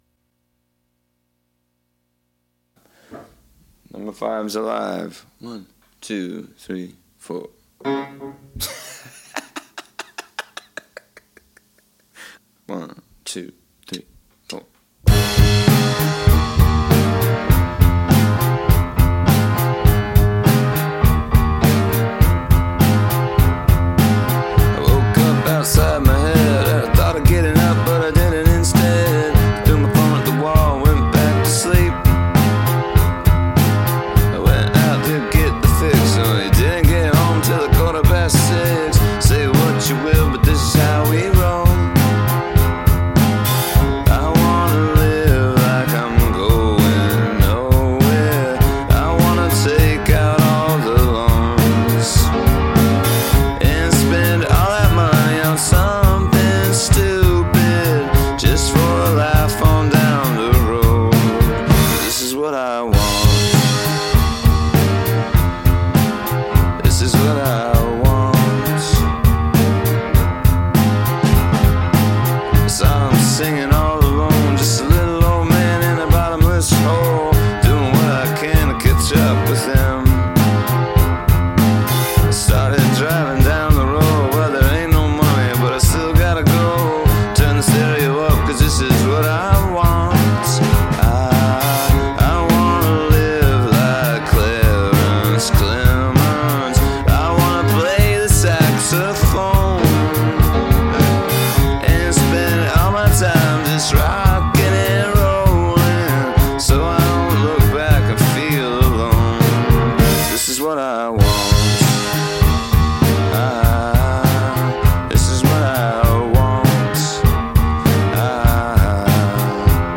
písničky pro piano